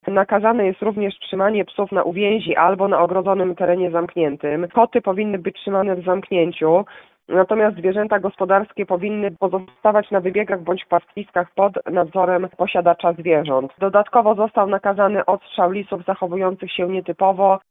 O szczegółach mówi wojewódzka lubelska lekarz weterynarii Monika Michałowska.